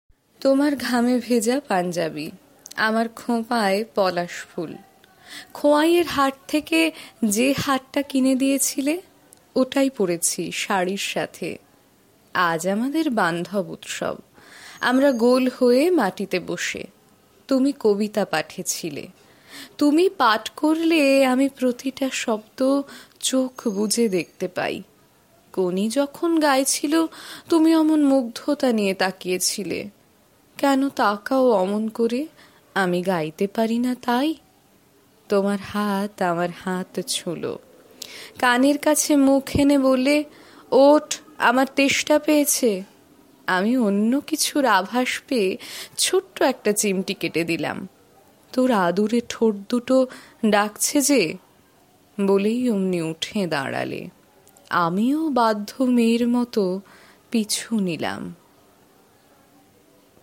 Modern Bengali Poems